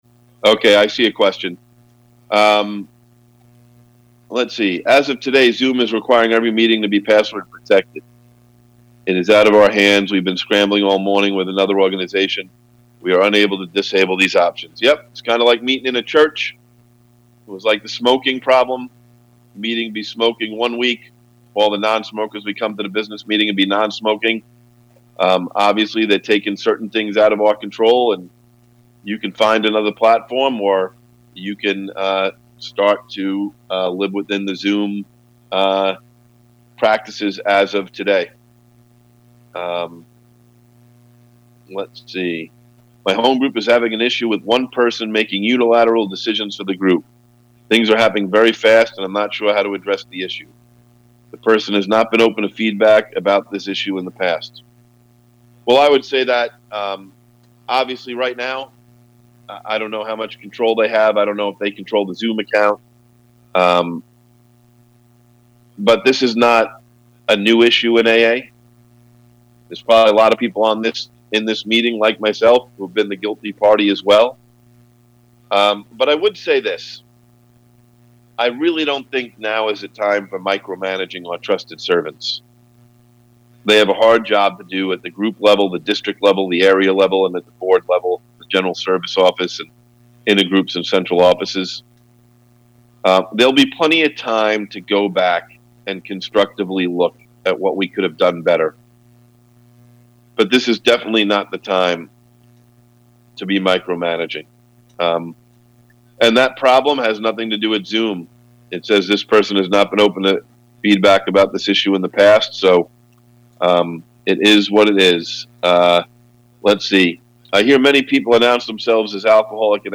GA Questions Traditions International Corona Service Conference of AA Worldwide 4-4-2020